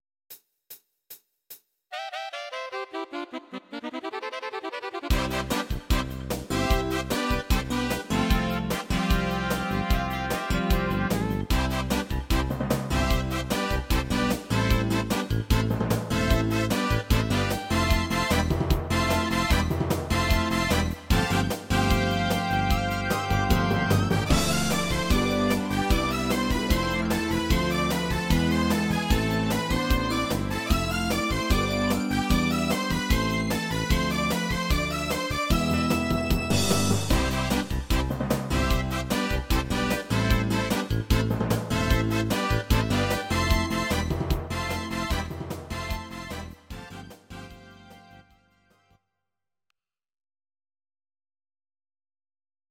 Audio Recordings based on Midi-files
Musical/Film/TV, Instrumental, 1980s